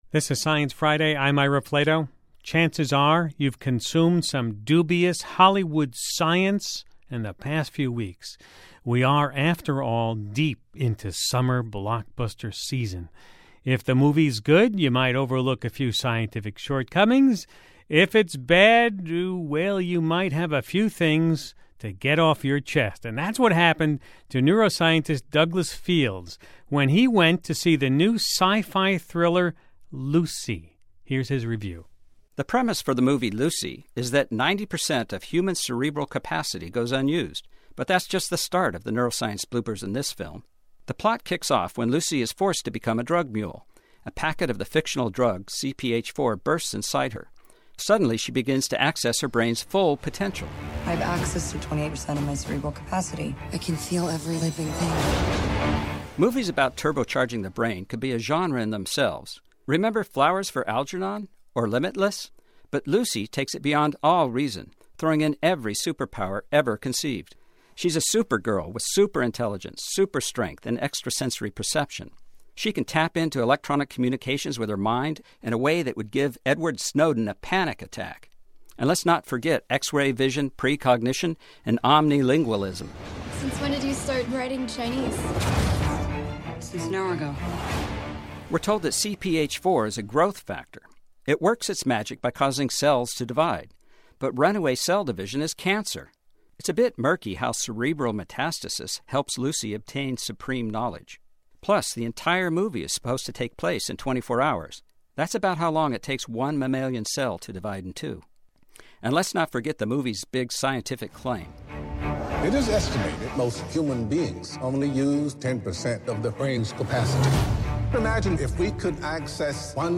A neurobiologist reveals sci-fi thriller Lucy’s neuroscience bloopers.